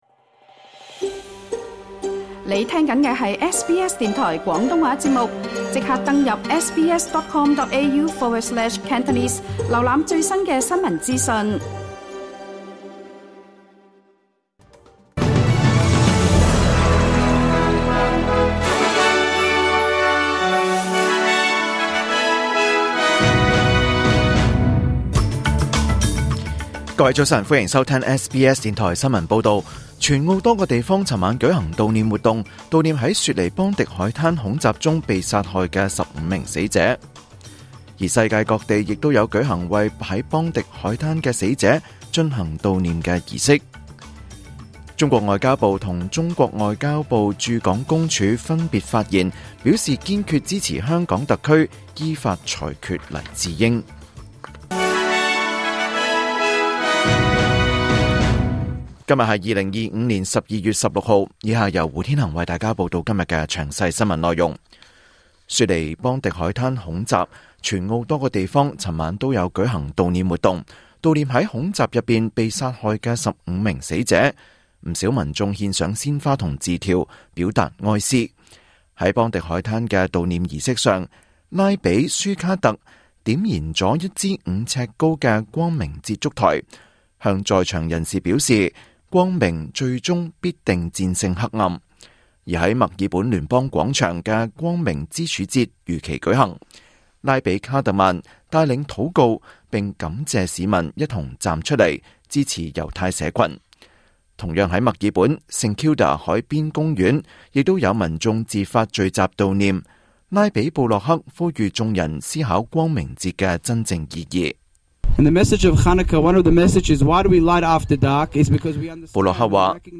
2025年12月16日SBS廣東話節目九點半新聞報道。